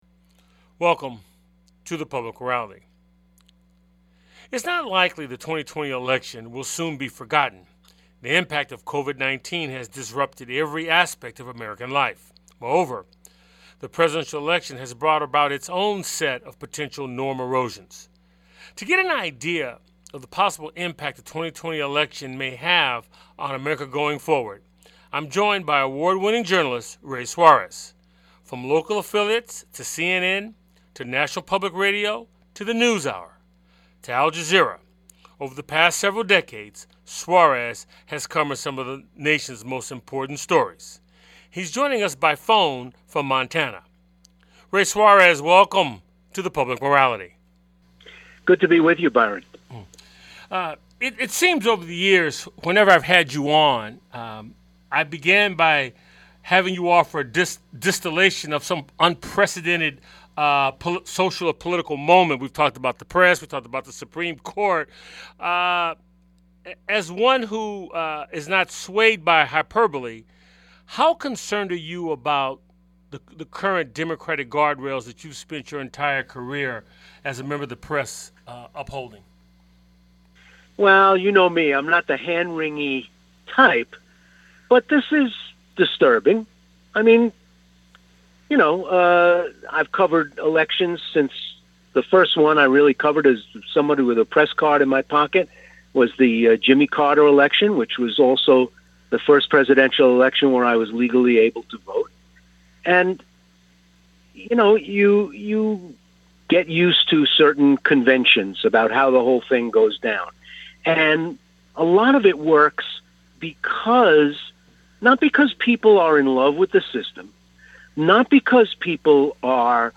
Guest on this show is Ray Suarez, Broadcast Journalist.&nbsp; Public Morality is a one-hour public affairs talk show